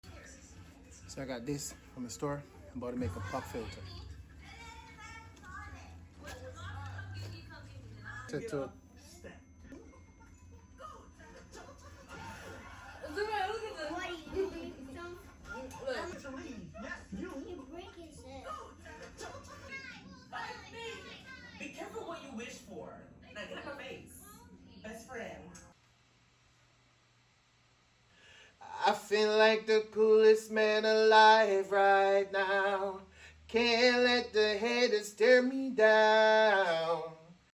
I was getting a lot of hollowed sounds whenever I use the regular pop filter so I created my own mic insulation